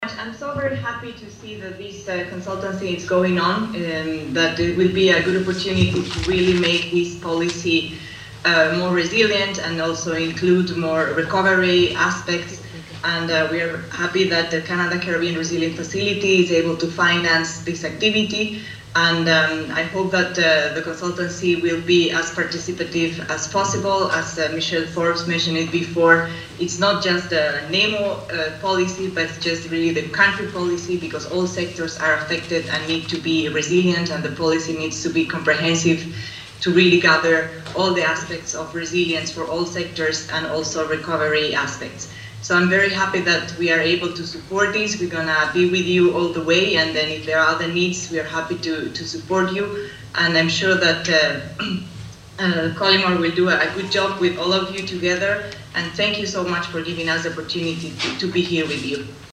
during the opening of a Stakeholders Orientation Consultation which was spearheaded by the National Emergency management Organization (NEMO), this week.